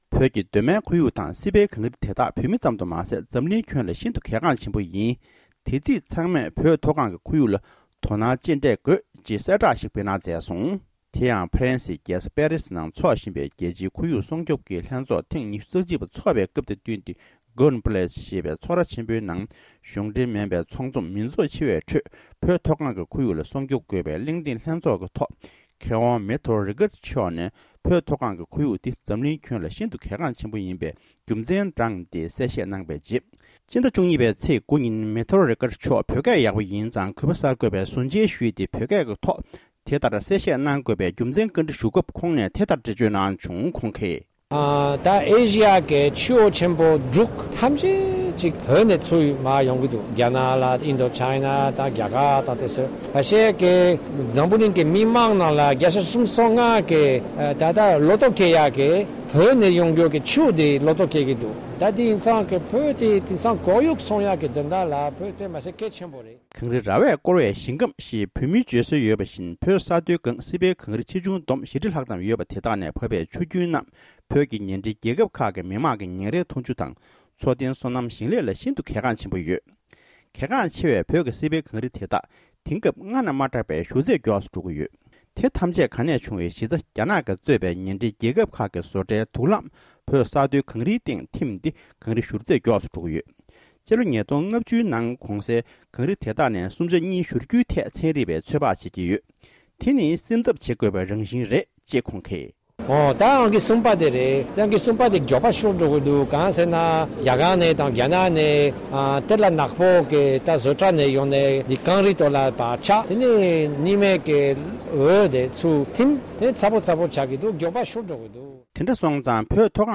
བོད་མཐོ་སྒང་གི་ཁོར་ཡུག་ལ་དོ་སྣང་དགོས། སྒྲ་ལྡན་གསར་འགྱུར།